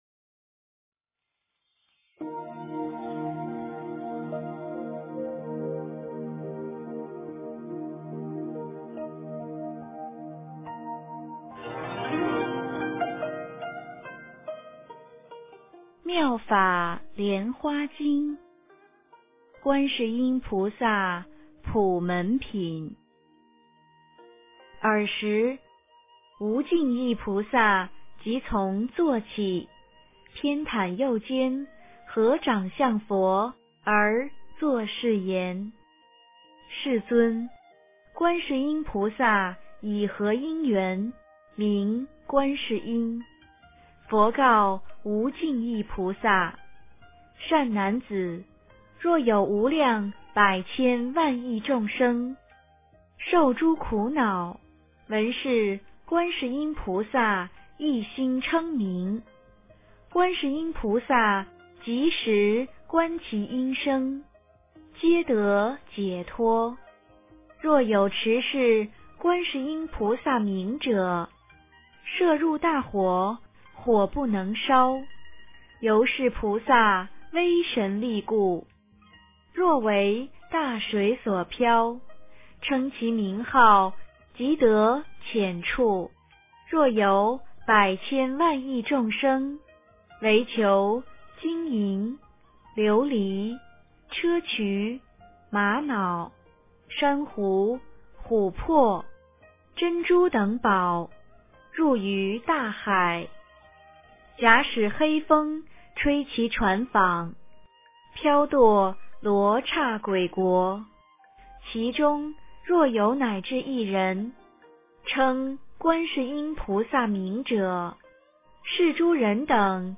观音菩萨普门品 - 诵经 - 云佛论坛